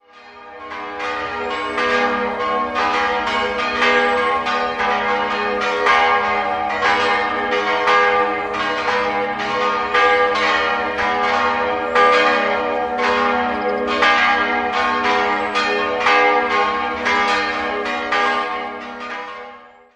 Sie wurde 1726, vermutlich an der Stelle eines gotischen Vorgängerbaus, im barocken Stil neu errichtet. 4-stimmiges Geläute: g'-b'(-)-c''-d'' Die Glocken 1, 2 und 4 wurden im Jahr 1951 von Georg Hofweber in Regensburg gegossen. Die zweitkleinste Glocke ist älteren Datums (nähere Angaben liegen derzeit nicht vor).